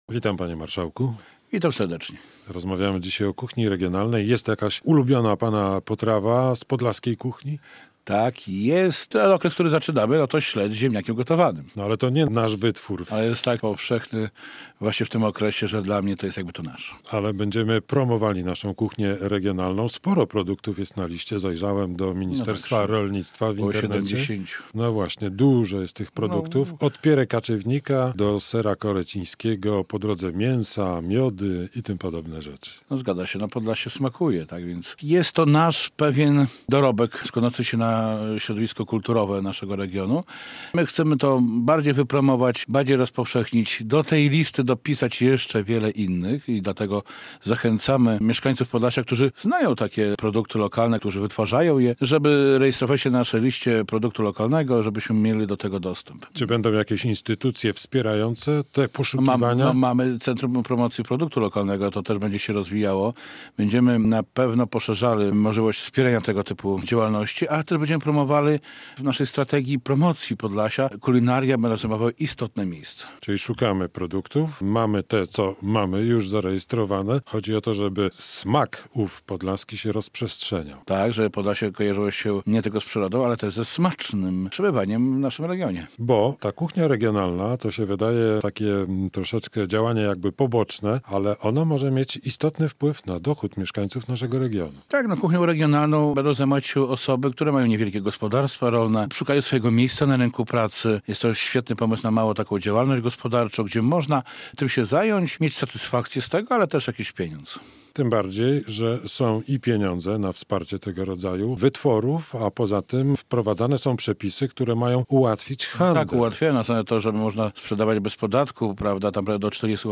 Radio Białystok | Gość | Stanisław Derehajło [wideo] - wicemarszałek województwa podlaskiego